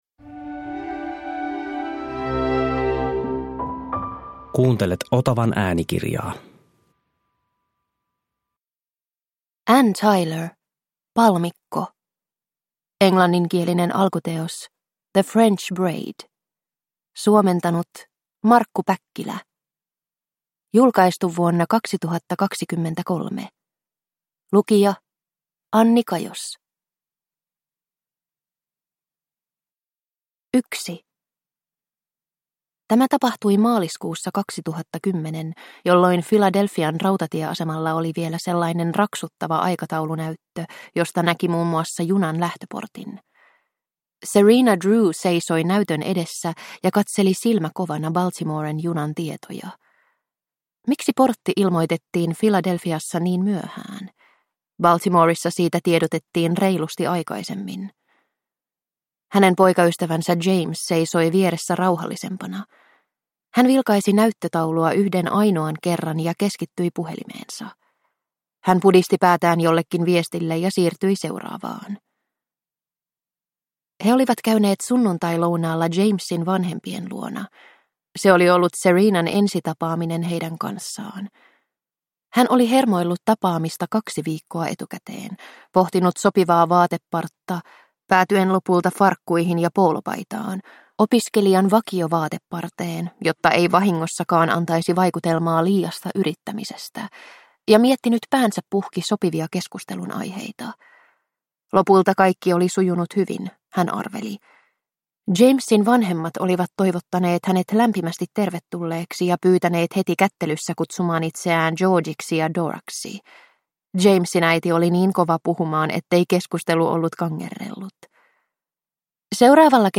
Palmikko – Ljudbok – Laddas ner